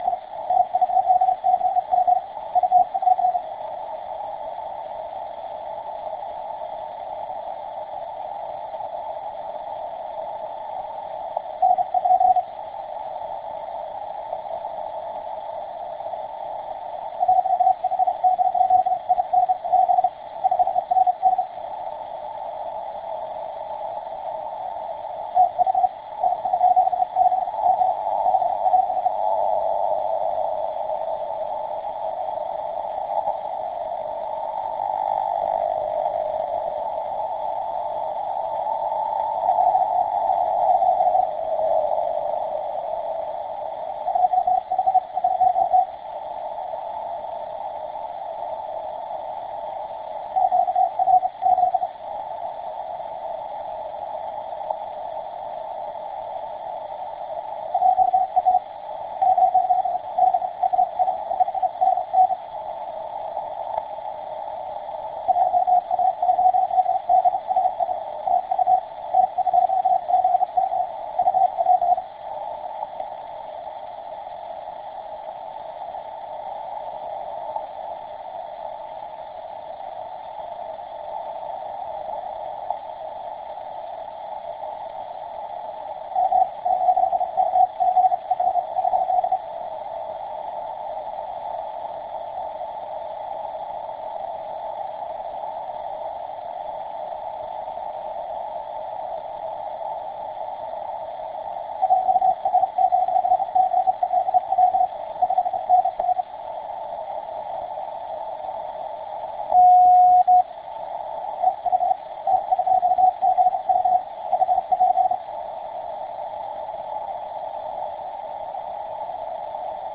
Auroral sigs on T2GM (30CW)